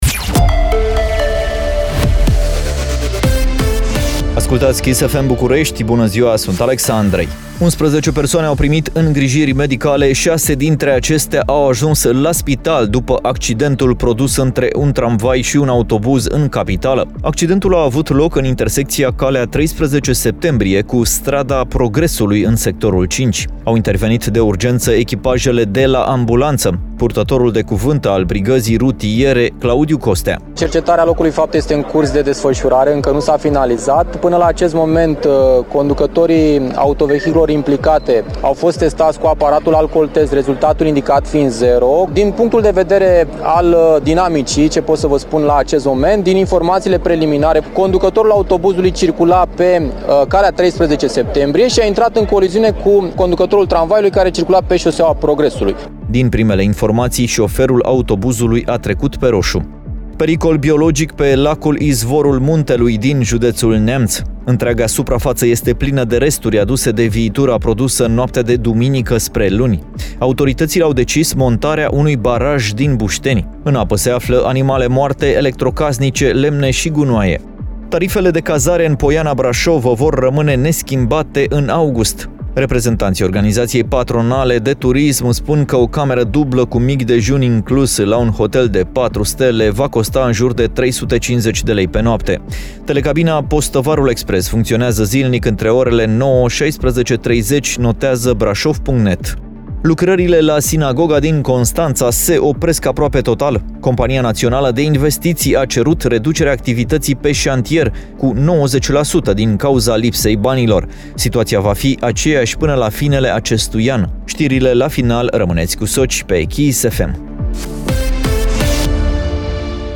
Știrile zilei